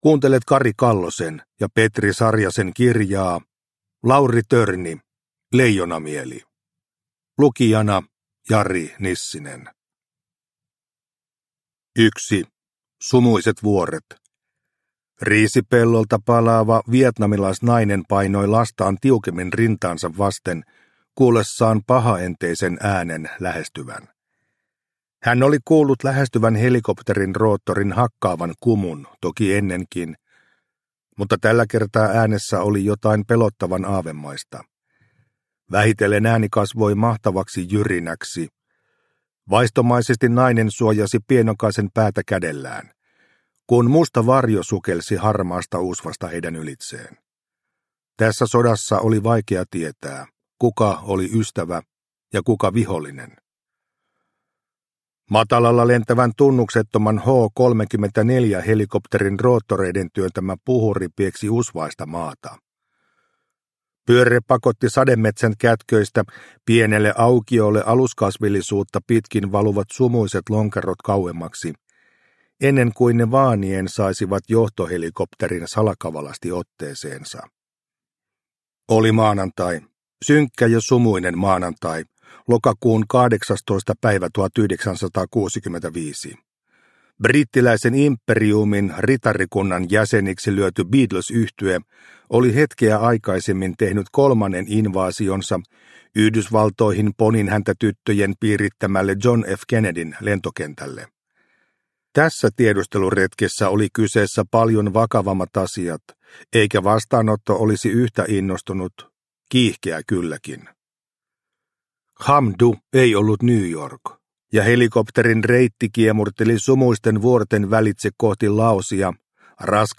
Lauri Törni – Ljudbok – Laddas ner